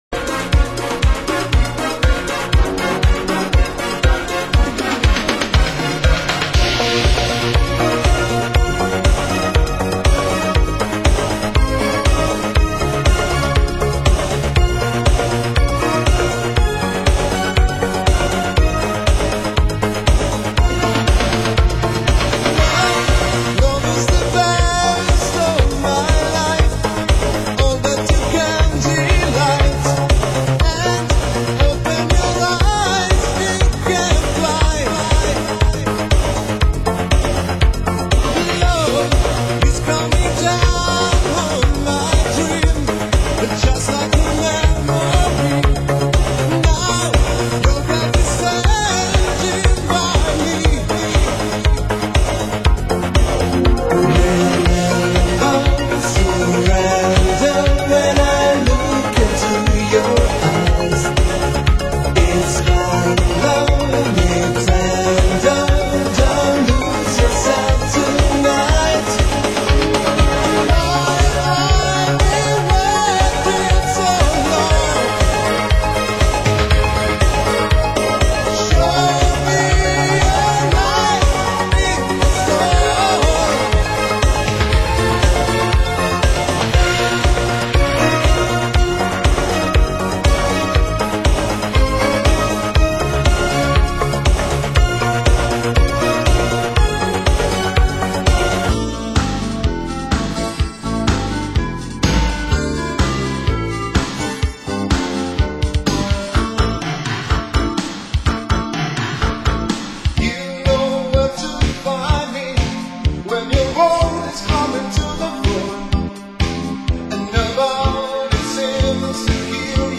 Genre: Italo Disco